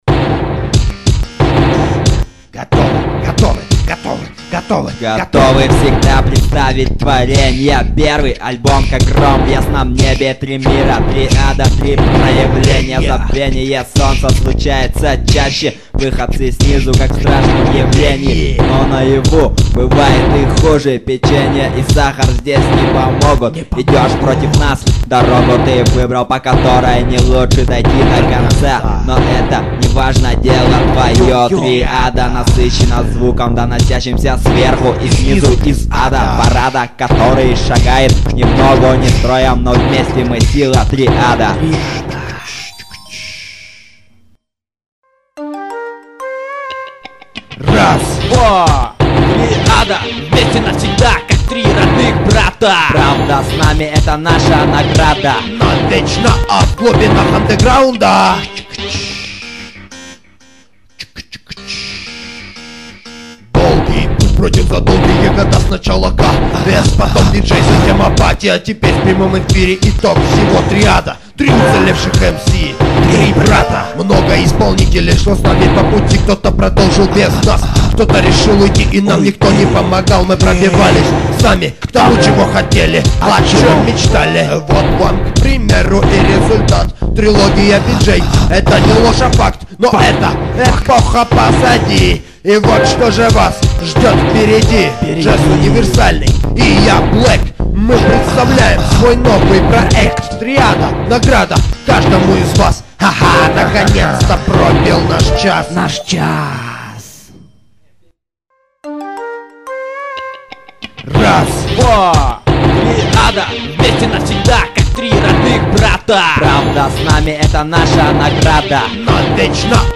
undeground rap
Гитара